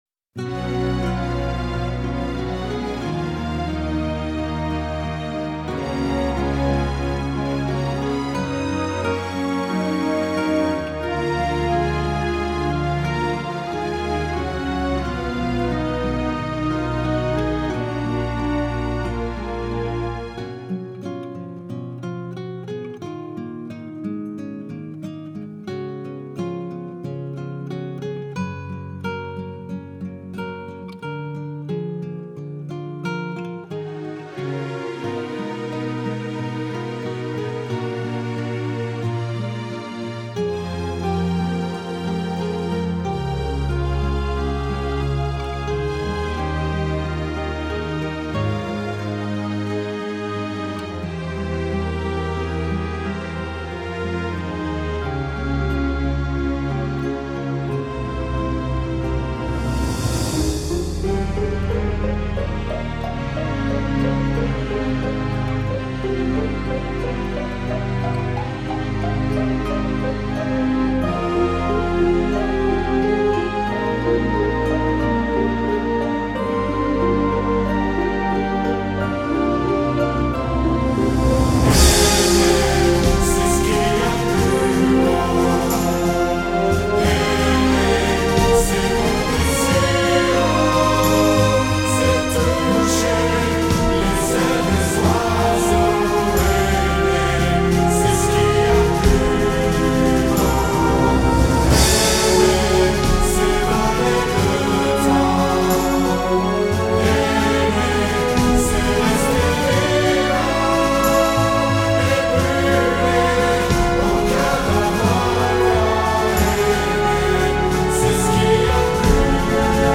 ◊ Фонограммы:
фр. бэки